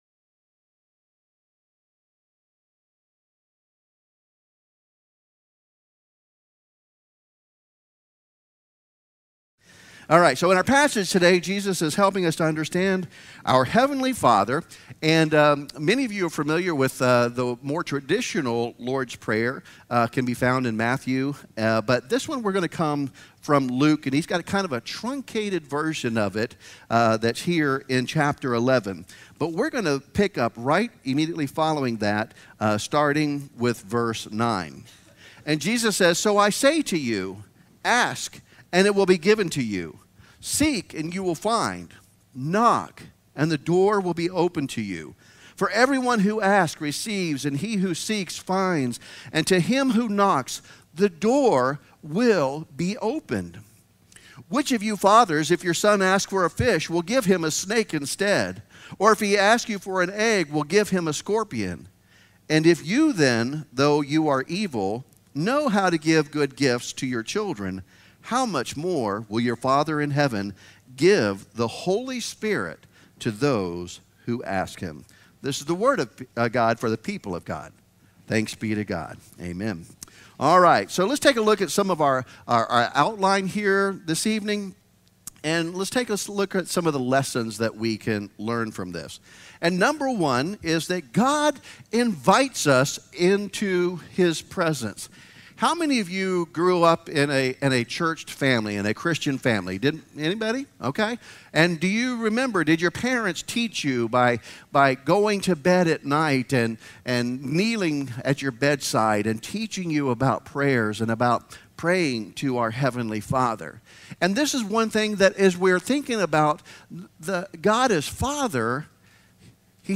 A fresh take on Saturday worship in our Fellowship Hall at 5:22 pm